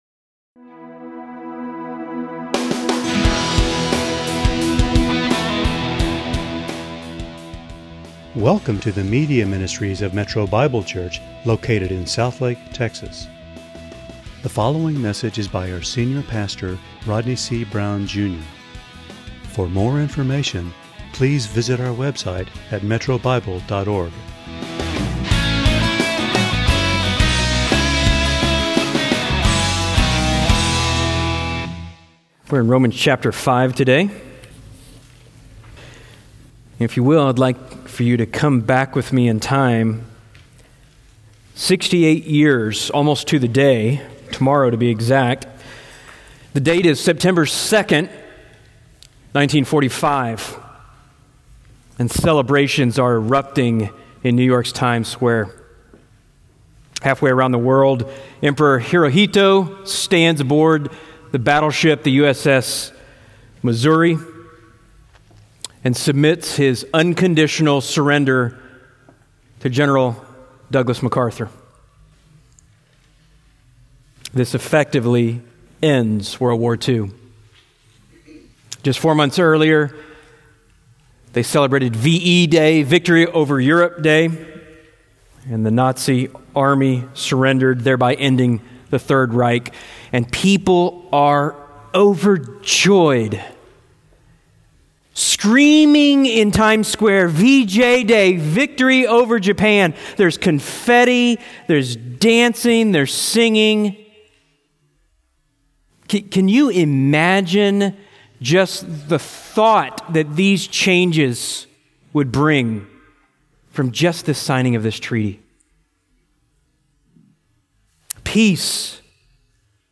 × Home About sermons Give Menu All Messages All Sermons By Book By Type By Series By Year By Book Benefits of Peace Peace with God changes everything.